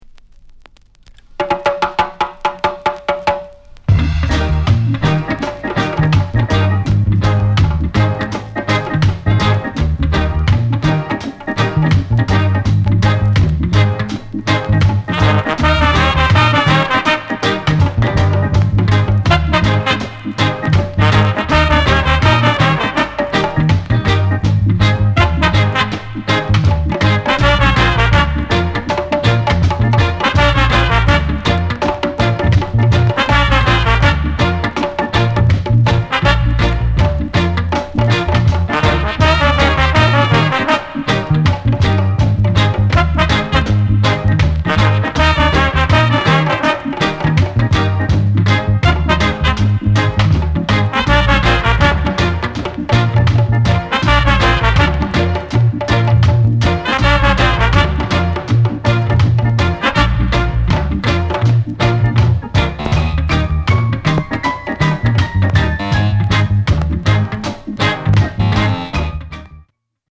ROCKSTEADY INST